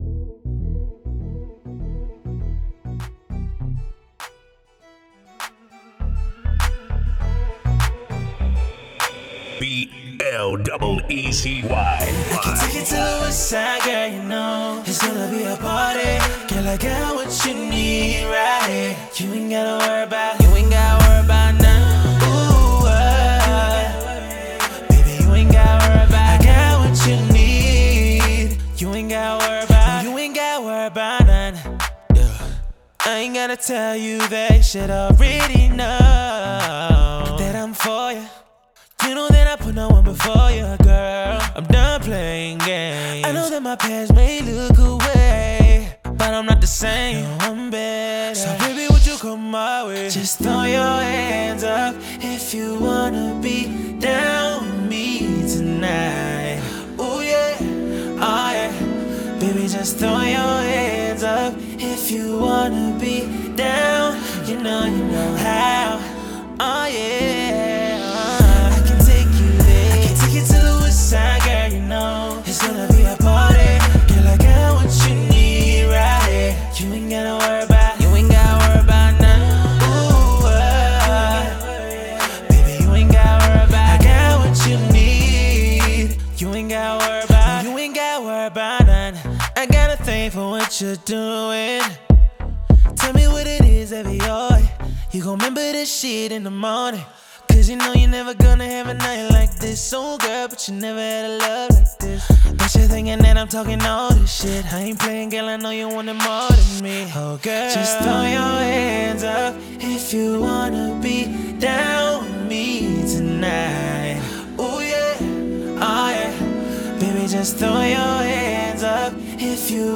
энергичная и зажигательная композиция
выполненная в жанре хип-хоп.